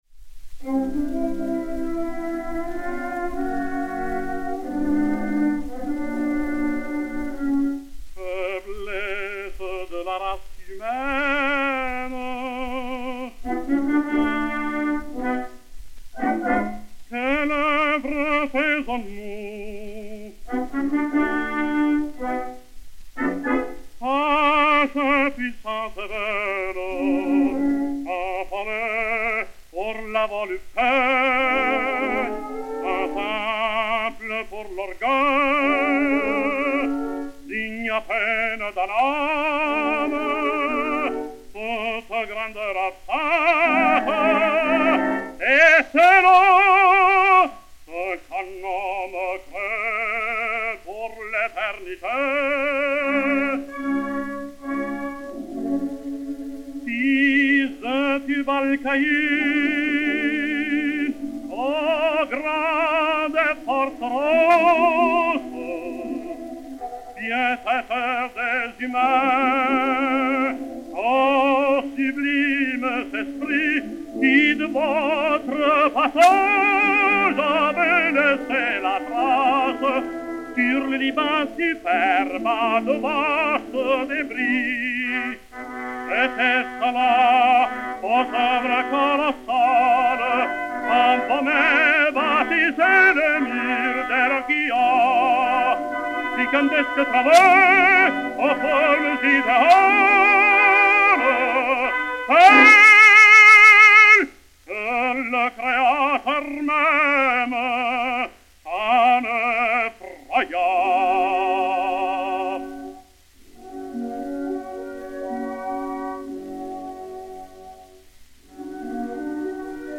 Orchestre
C-17125, réédité sur Gramophone DB 145, enr. à Camden, New Jersey, le 02 mai 1916